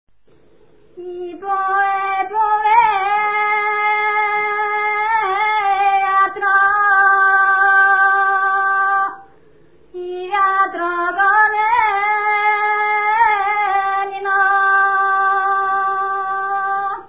музикална класификация Песен
размер Безмензурна
фактура Едногласна
начин на изпълнение Солово изпълнение на песен
функционална класификация Трудови (навън)
битова функция На жетва
фолклорна област Южна България (Западна Тракия с Подбалкана и Средна гора)
място на записа Отец Паисиево
начин на записване Магнетофонна лента